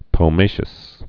(pō-māshəs)